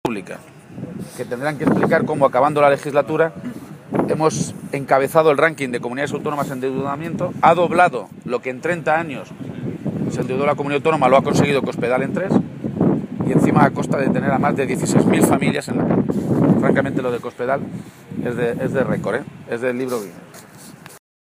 García-Page se pronunciaba de esta manera esta mañana, en Cuenca, en una comparecencia ante los medios de comunicación, en la que aseguraba que lo más triste de este tipo de cosas (derrumbes de techos de hospitales y despidos como ejemplo del desmantelamiento de los servicios públicos esenciales) es que han ocurrido no solo después de que Cospedal prometiera que no iba a traspasar las líneas rojas de sus recortes en la Sanidad o la Educación, sino “después de que ver cómo vamos a terminar la legislatura con 6.000 millones de euros más de deuda pública. Es decir, que con Cospedal hemos acumulado más deuda pública en tres años que en los treinta anteriores de la historia” de Castilla-La Mancha.